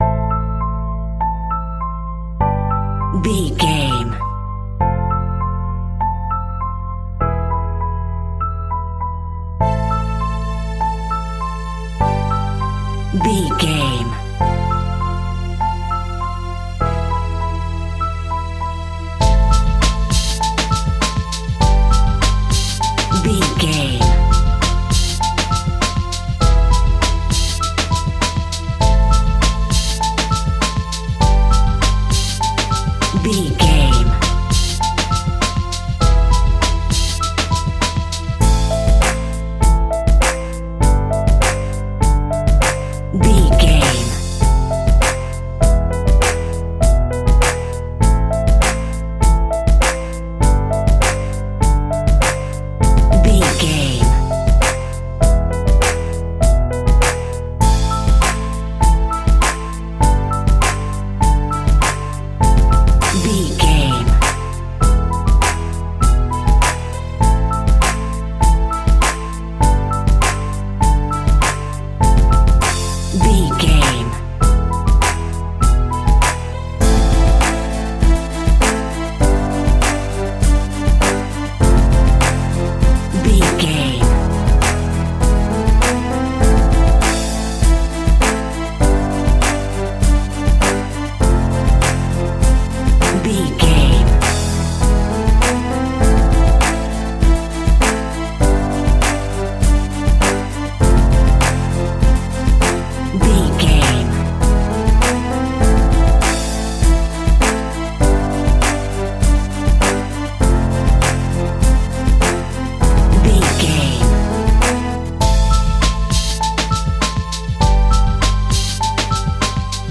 Rap Beat Instrumental Music Cue.
Aeolian/Minor
hip hop
chilled
laid back
groove
hip hop drums
hip hop synths
piano
hip hop pads